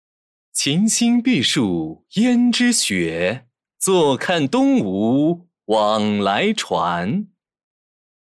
声优以年轻帅气且自信的声音，配合清越的声线，完美诠释了其“谈笑间樯橹灰飞烟灭”的智将风范。
标签：男人 年轻 帅气